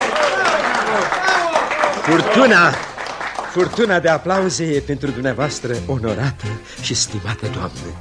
furtuna-de-aplauze-e-pentru-dv-onorata-si-stimata-doamna.mp3